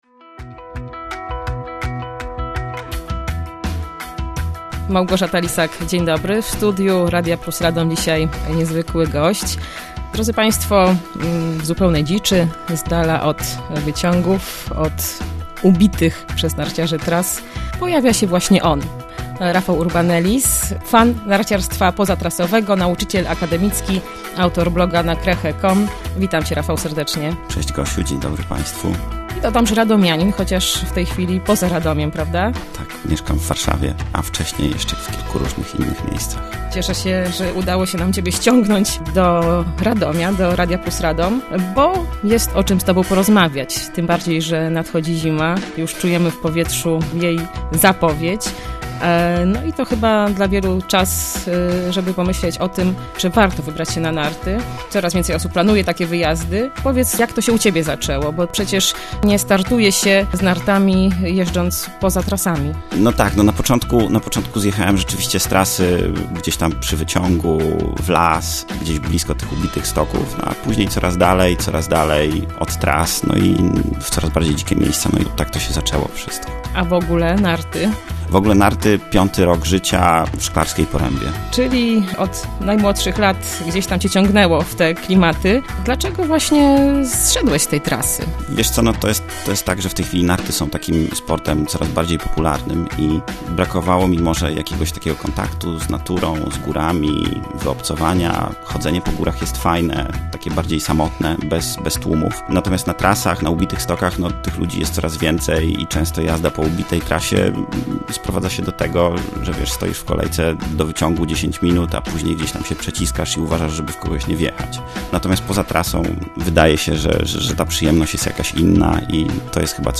Wywiad red.
wywiad-czesc1.mp3